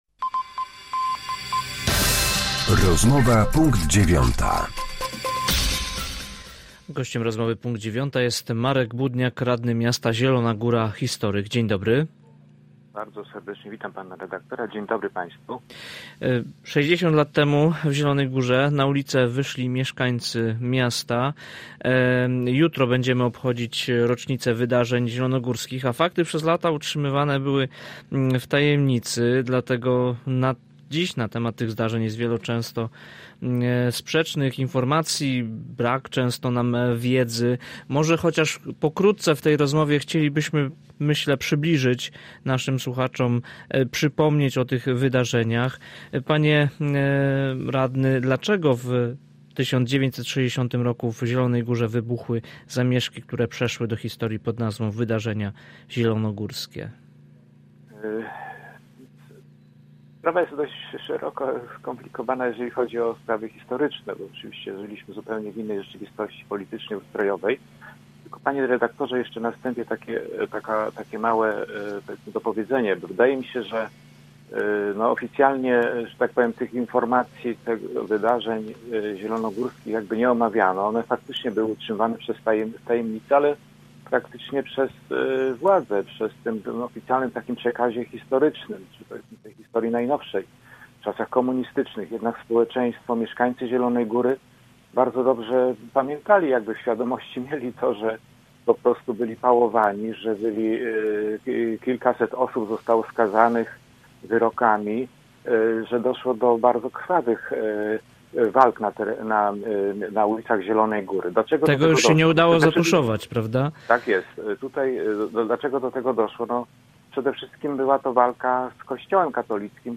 Z historykiem, radnym klubu Prawo i Sprawiedliwość rozmawia